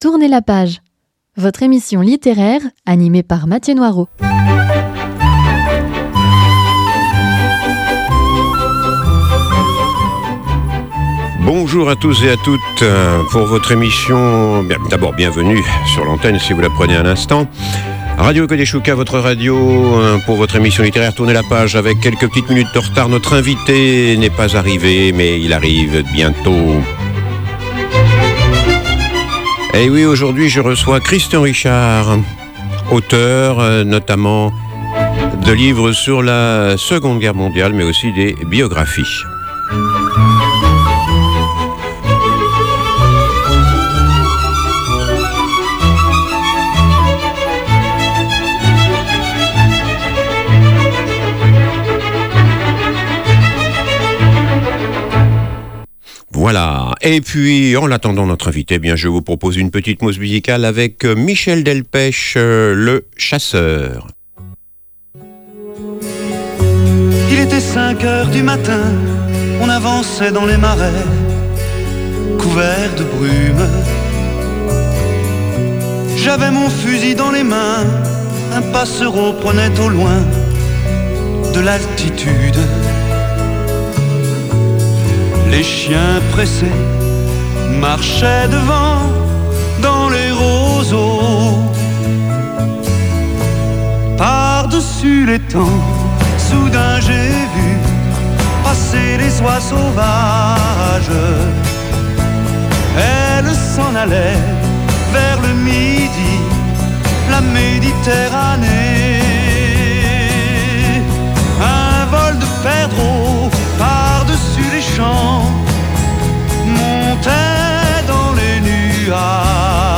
Émission littéraire avec un·e invité·e : auteur ou autrice qui nous parle de son métier, de ses ouvrages ou de son dernier livre édité.